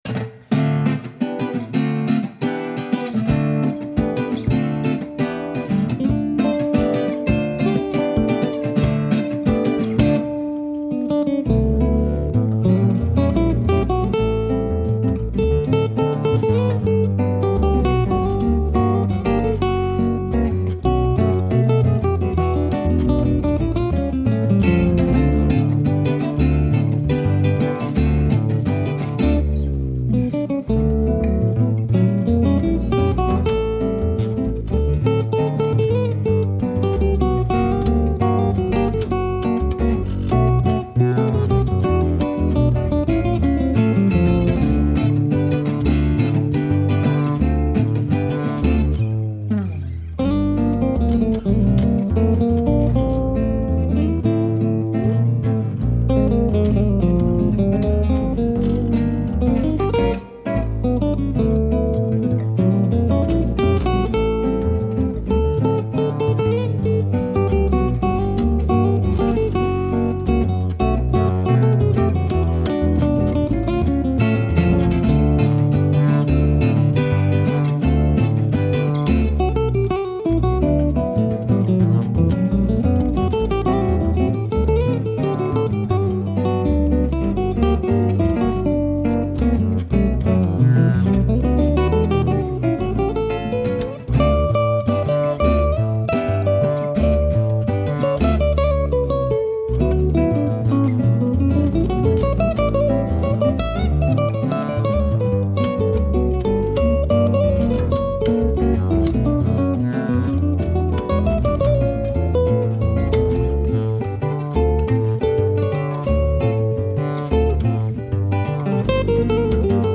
guitare solo